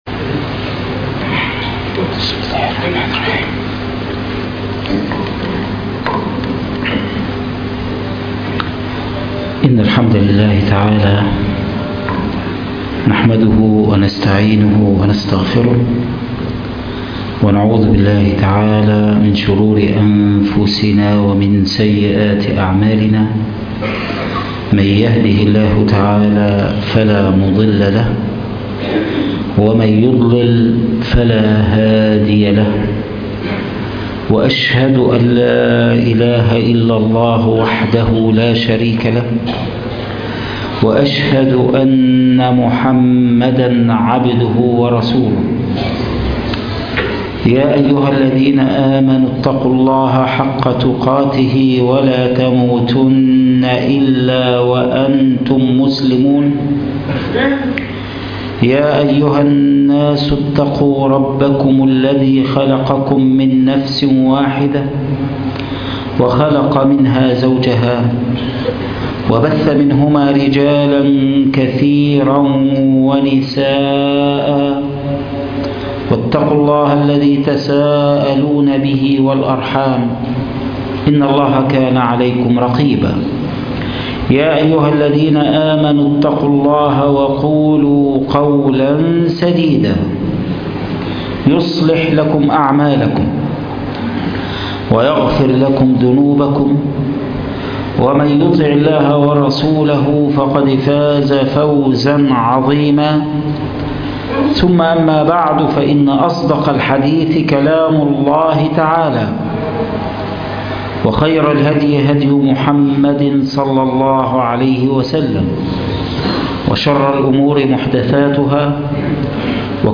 ما بين الجنة والنار ( خطب الجمعة